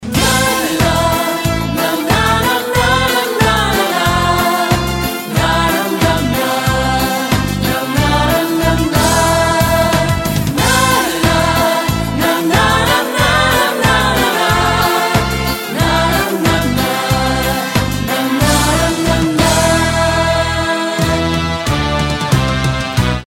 Нарезки шансона